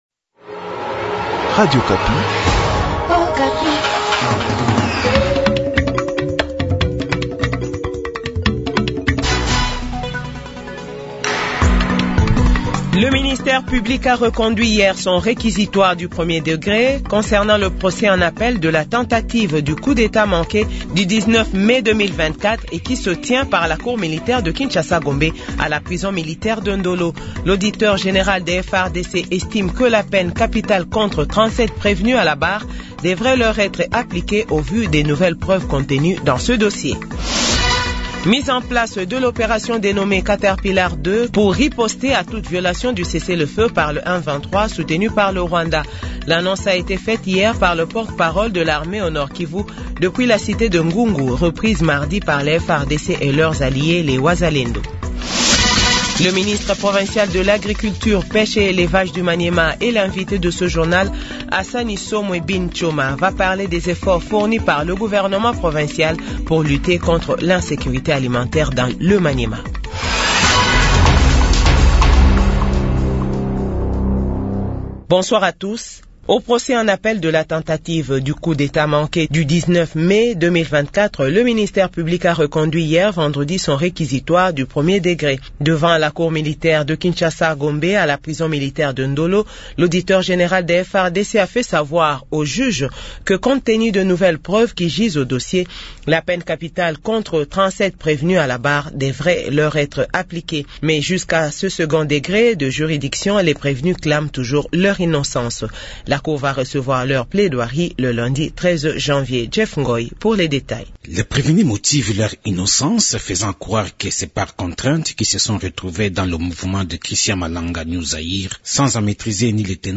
Journal 18h00